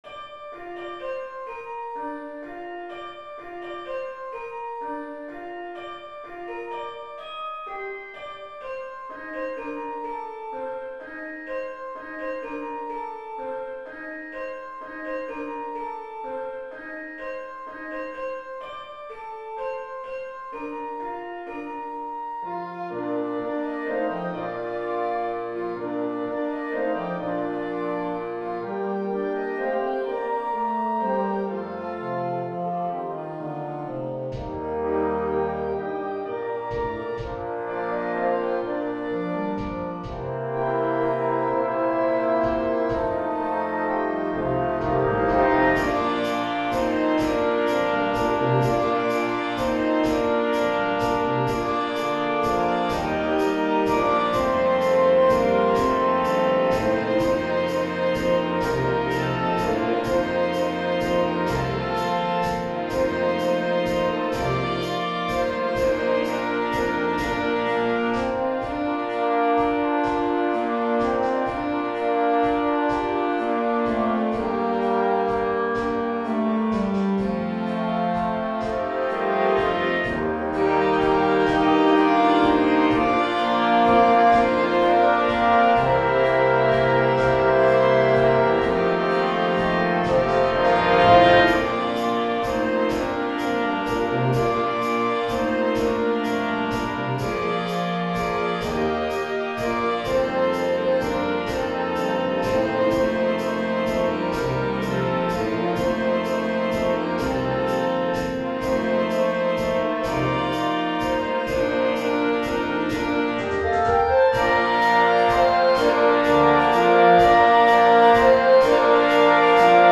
Gattung: Weihnachts-Medley
Besetzung: Blasorchester